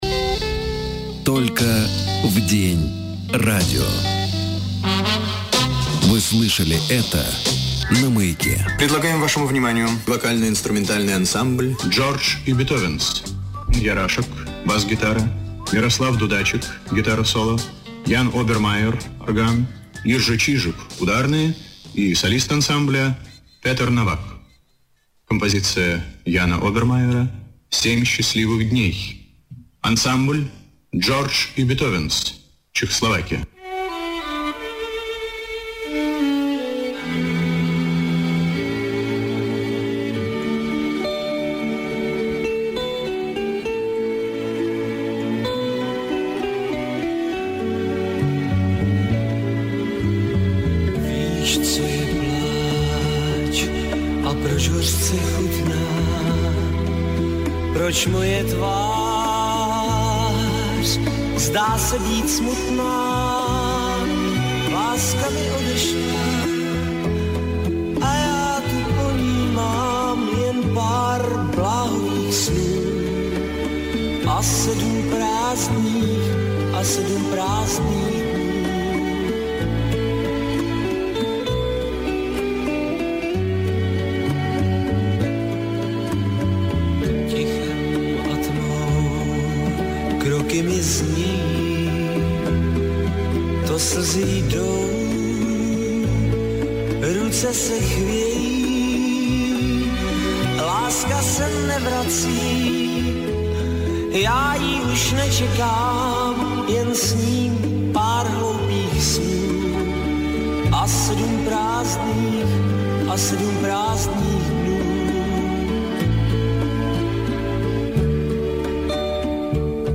Слышно, как "склеен" комментарий в этой песне!!!
Кстати, они зачем-то ускорили записи, у нас они медленнее.